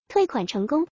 refund_success.wav